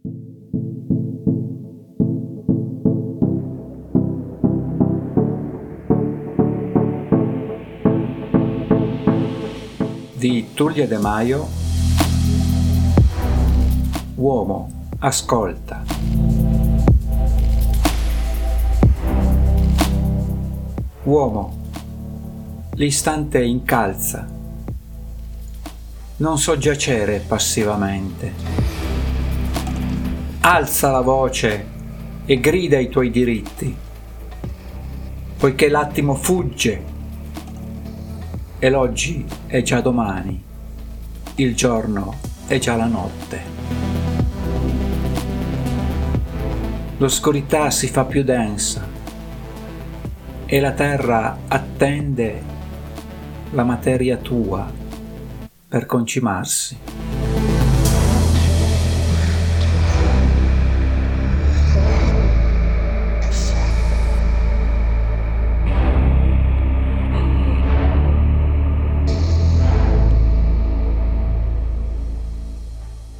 Eona emotional ambient pop